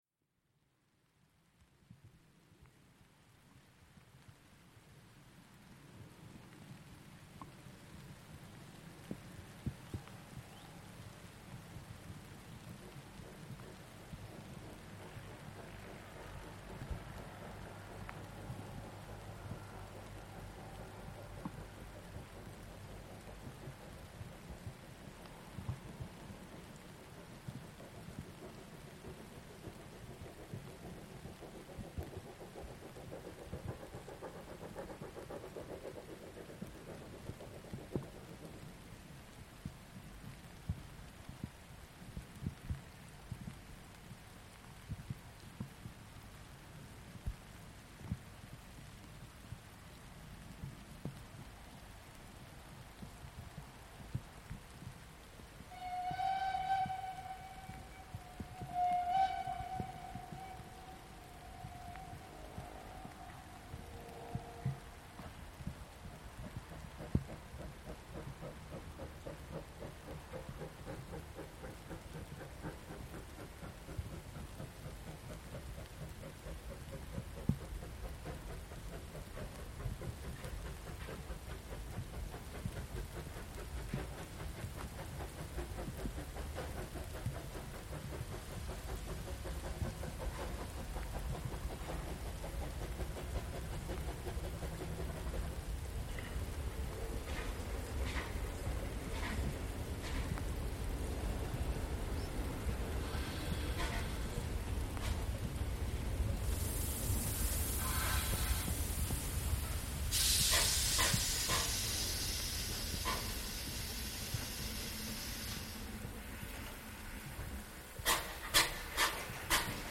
98 886 Tv mit Zug von Fladungen nach Mellrichstadt, Halt und Abfahrt Stockheim im Regen..., um 15:38h am 03.08.2025.   Hier anhören: